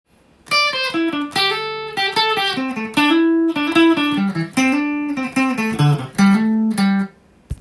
エレキギターで弾ける【歌うAマイナーペンタトニックスケール】シーケンスパターン【オリジナルtab譜】つくってみました
【歌うAマイナーペンタトニックスケール】シーケンスパターン